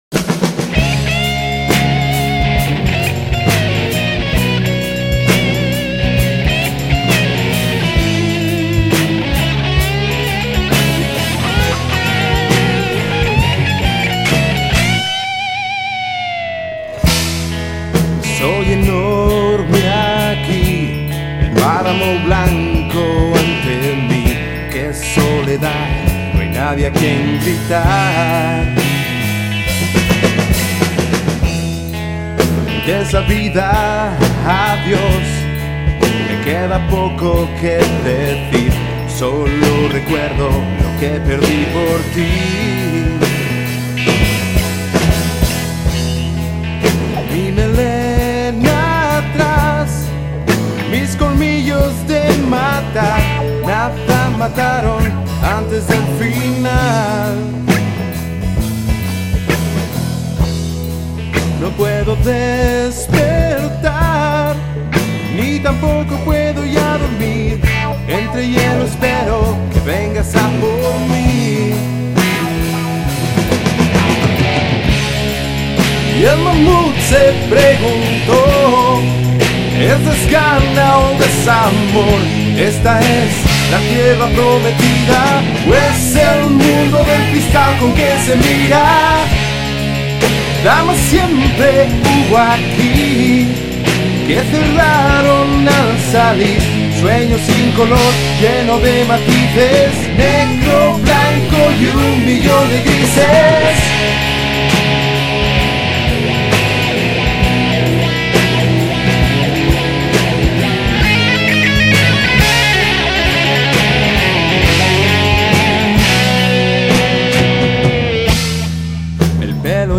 vocalista
bajo
guitarra